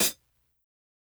HIHAT_RESTCL.wav